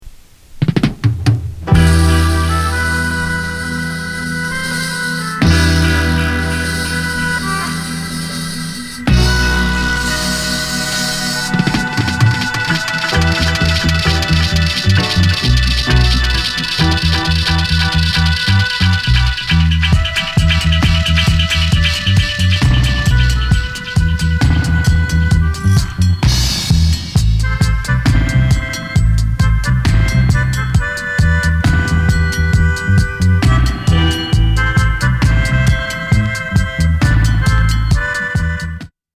ヘビー・ウェイト・ステッパー・ダブ＆メロディカ！！